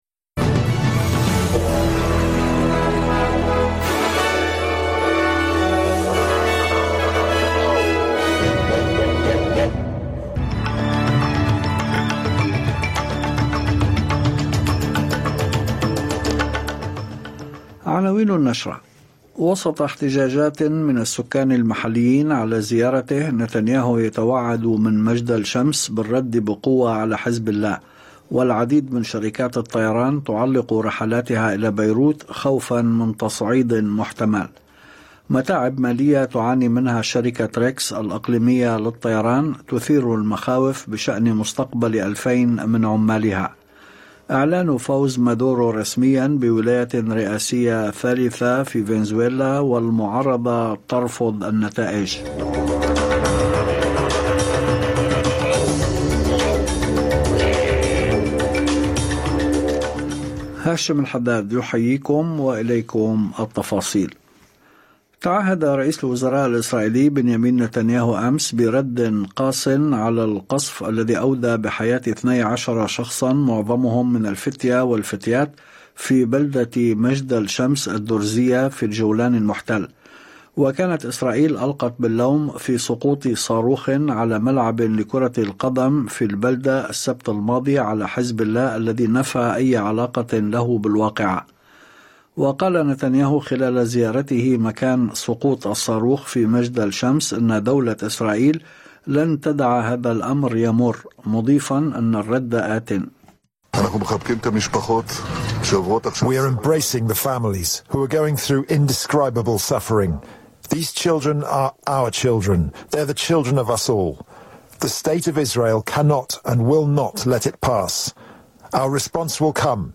نشرة أخبار المساء 30/07/2024